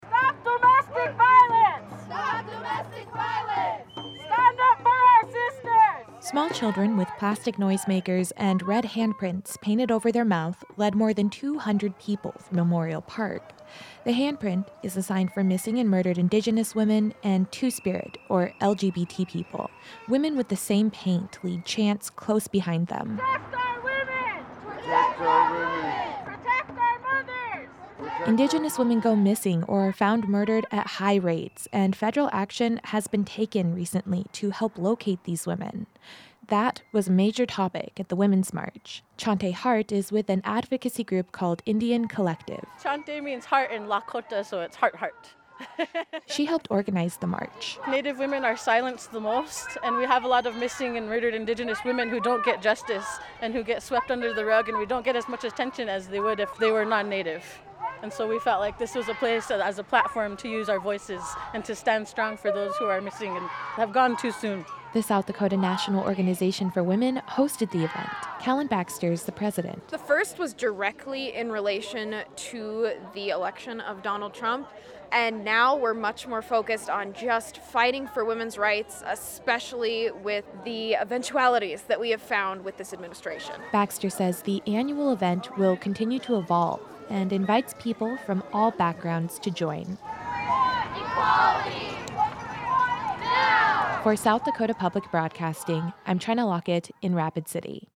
Small children with plastic noisemakers and red hand prints painted over their mouth lead more than 200 people through memorial park.
Women with the same paint lead chants close behind them.